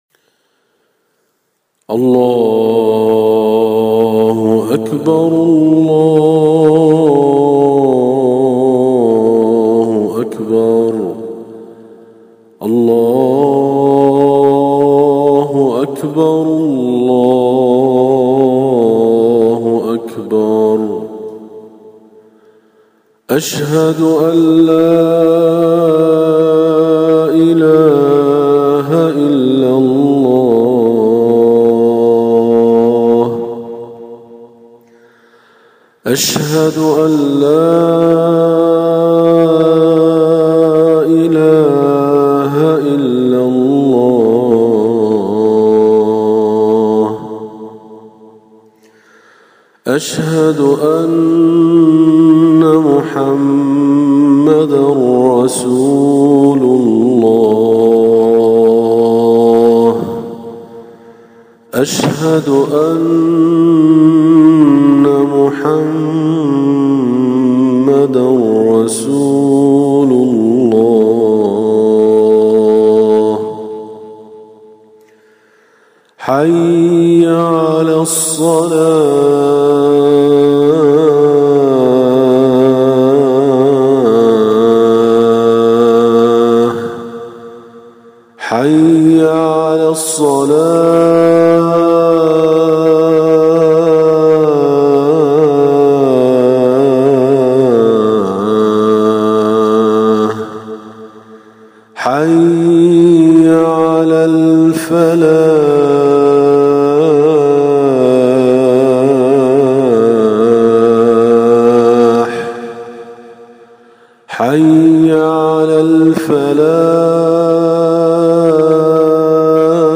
أناشيد ونغمات
عنوان المادة آذان بصوت الشيخ